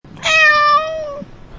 Download Cat Meow sound effect for free.
Cat Meow